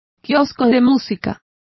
Complete with pronunciation of the translation of bandstand.